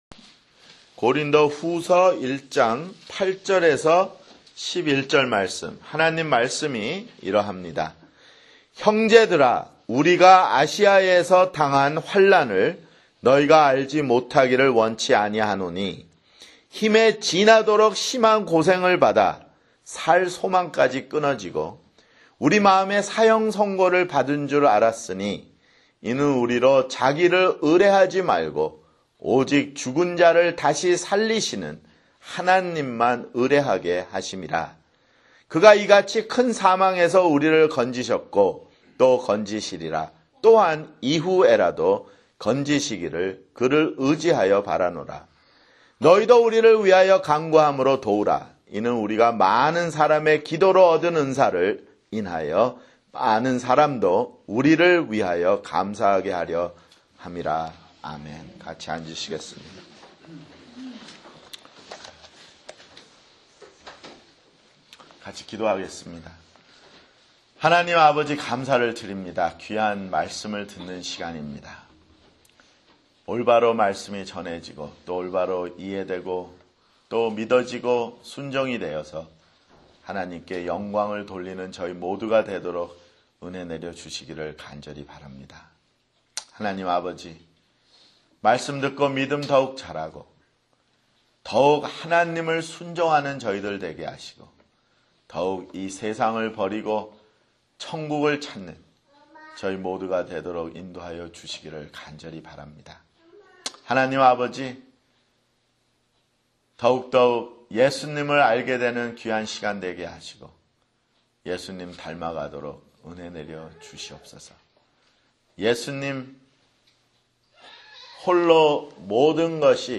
[주일설교] 고린도후서 (8)